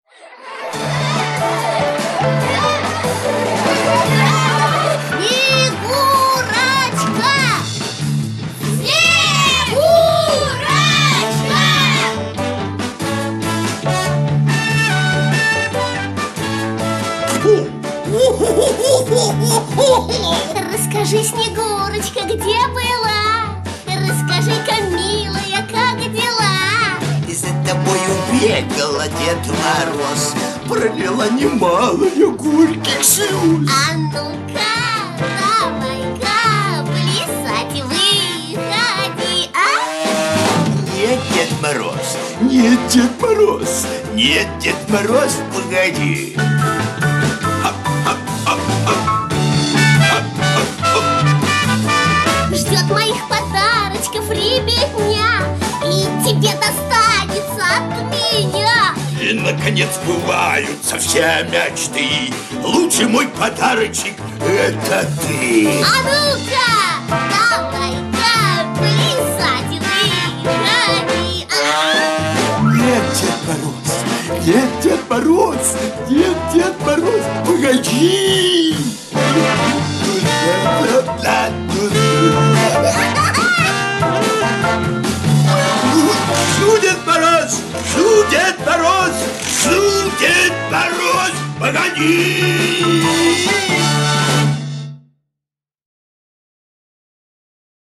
песня из мультфильма.